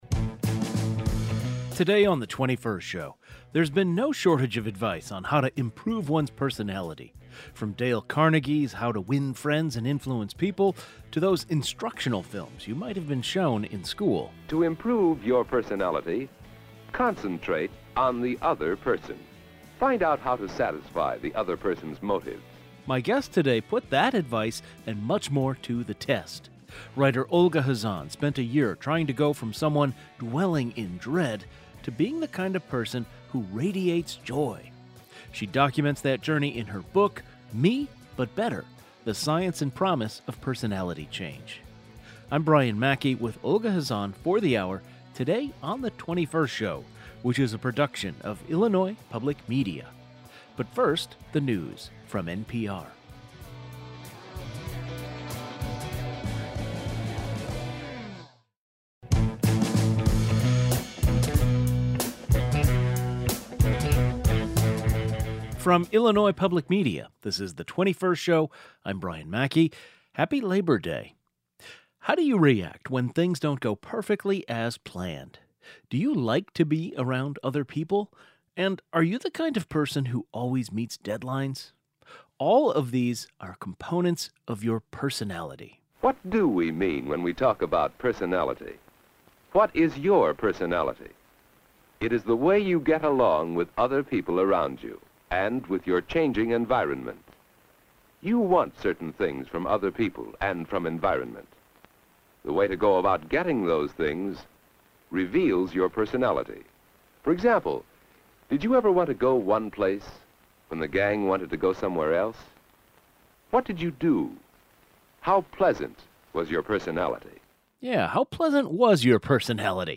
The 21st Show is Illinois' statewide weekday public radio talk show, connecting Illinois and bringing you the news, culture, and stories that matter to the 21st state.
Today's show included a rebroadcast of the following "best of" segment, first aired June 17, 2025: Author documents attempt to change her own personality in new book.